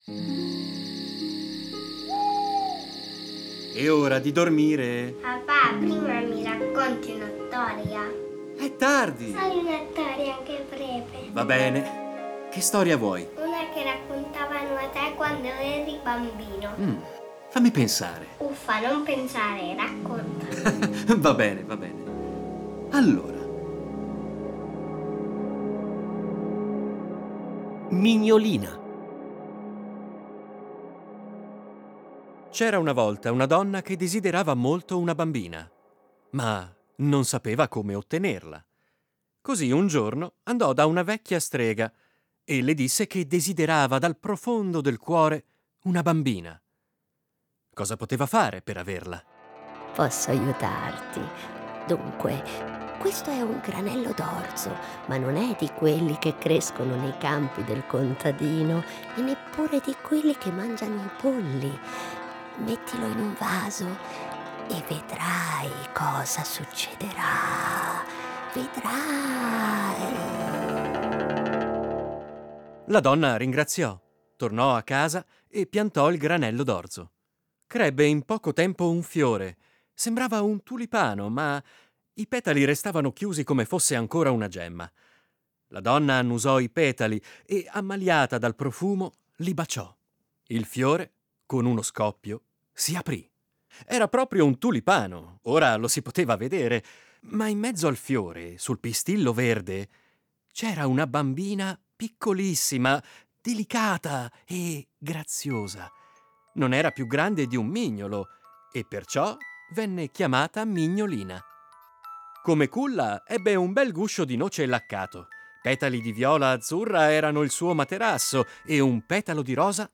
A partire dai testi originali un adattamento radiofonico per fare vivere i bambini storie conosciute, ma un po' dimenticate.